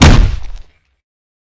Punch_Hit_13.wav